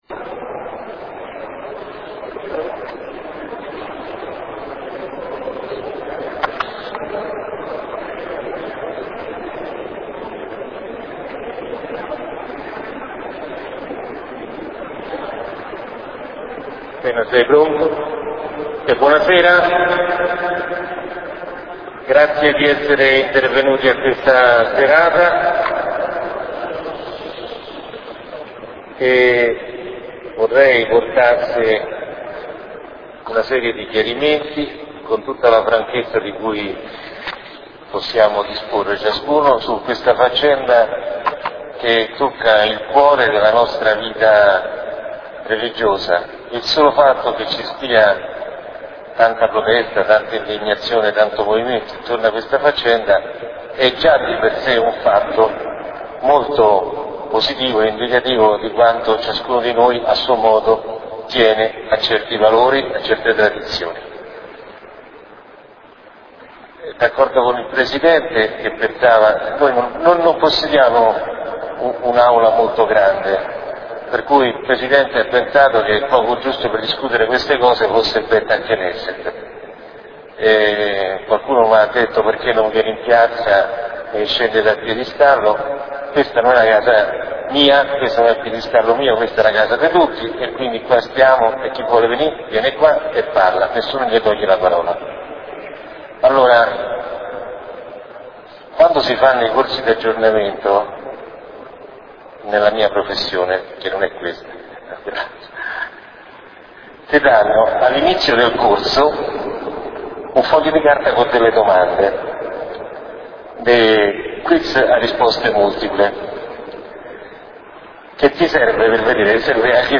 L�audio della serata al Tempio Maggiore, 22 marzo 2010